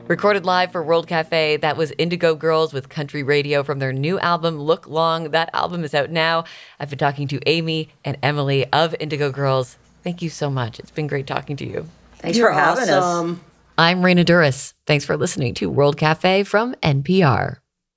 (captured from webcast)
11. interview (0:18)